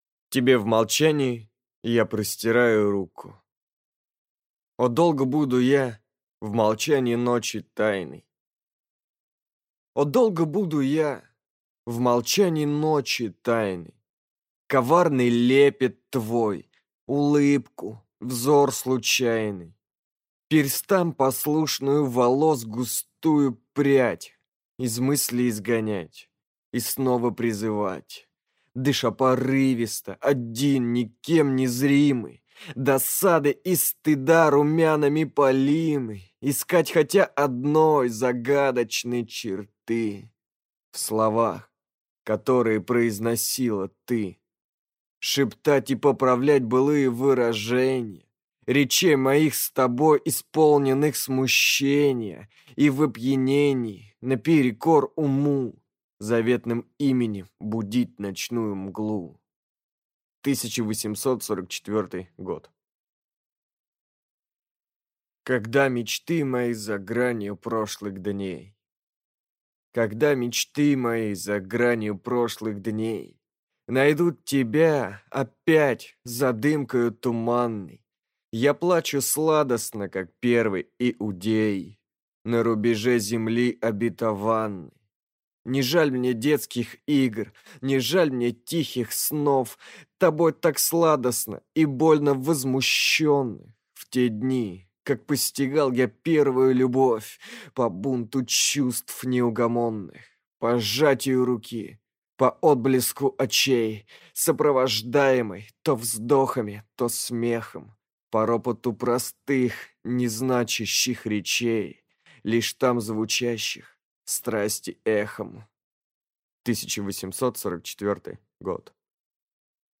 Аудиокнига Как будто вне любви есть в жизни что-нибудь…